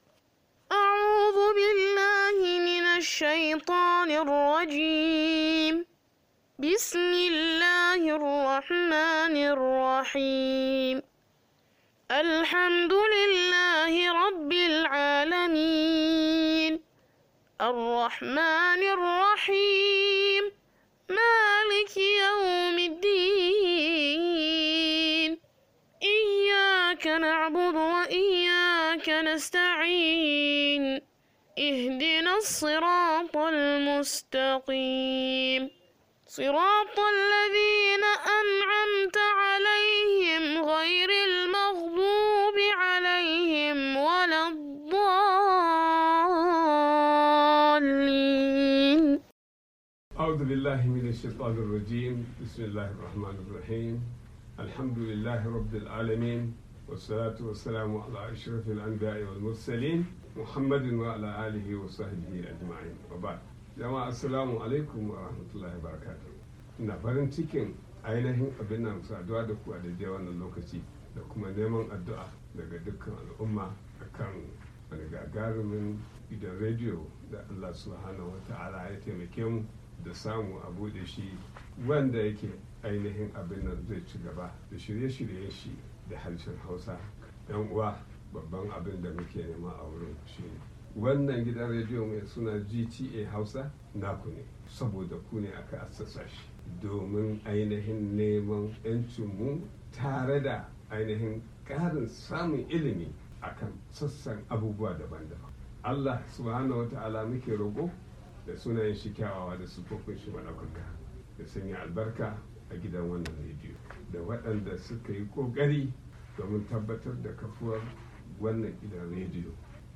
Wannan shirin na yau wanda aka kaddamar da gidan Rediyon “Amurka Ke Magana.”
A kowace rana da karfe 6:00 na safe agogon Najeriya, Nijar, Kamaru, da Chadi karfe 5:00 agogon GMT/Ghana muna gabatar da shirye-shiryen mu da suka hada da labarai, rahotanni da dumi-duminsu, don ilmantarwa, nishadantarwa, tare da fadakarwa.